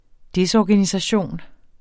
Udtale [ ˈdesˌɒːganisaˌɕoˀn ]